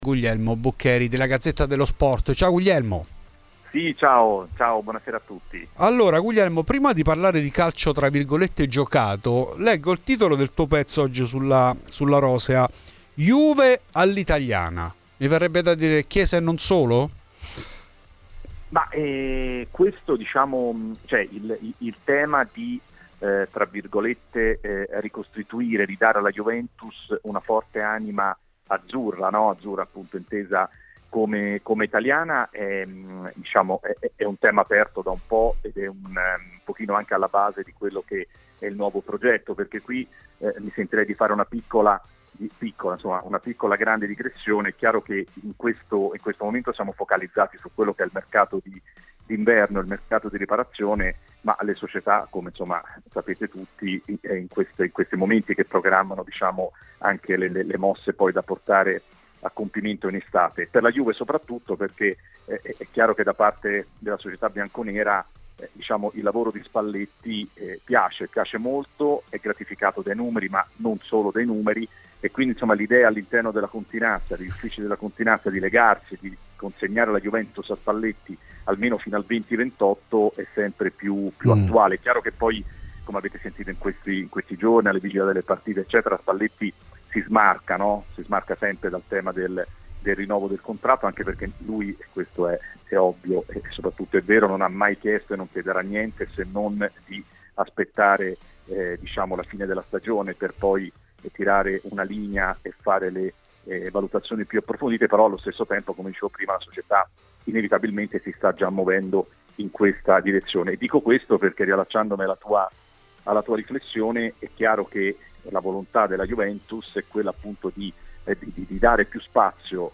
A parlare di mercato a Radio Bianconera , nel corso di Fuori di Juve , il giornalista